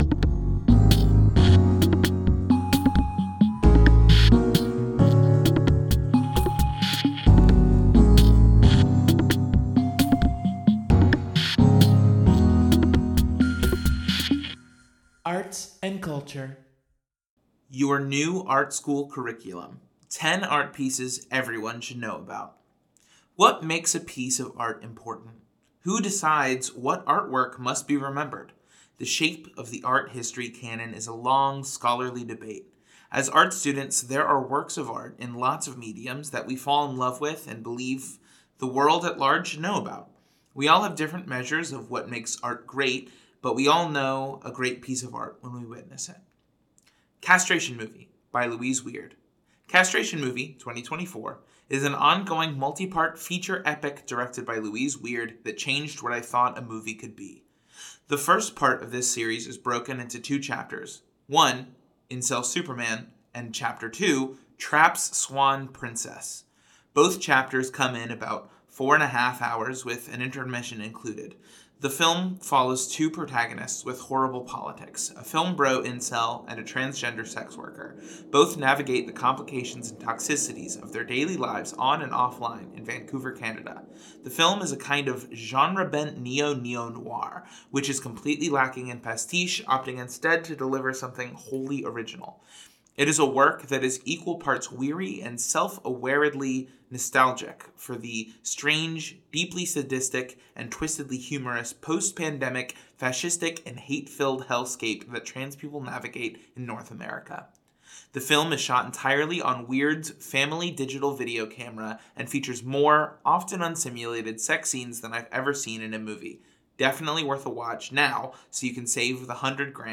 The text of this article was read aloud and recorded for your greater accessibility and viewing pleasure: